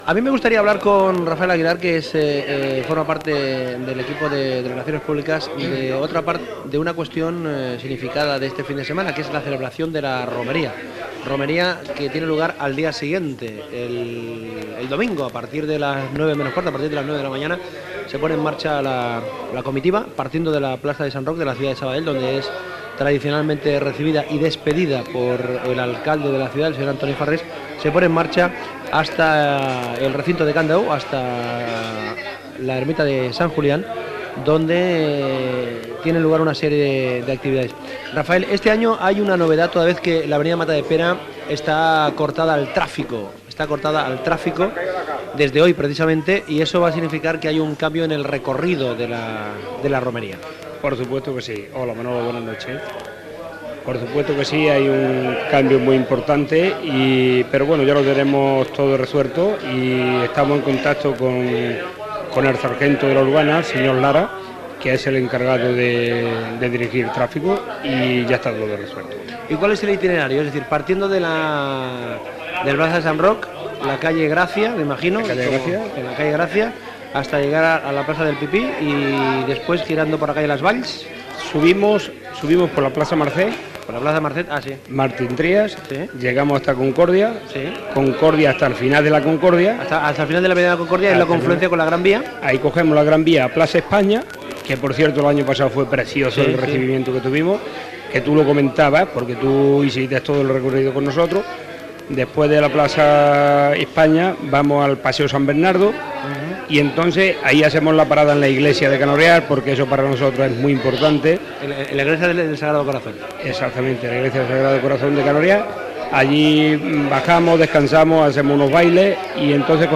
Programa especial amb motiu de la Romeria de Sant Isidre Llaurador de l'Agrupación Andaluza San Sebastián de los Ballesteros.
Entreteniment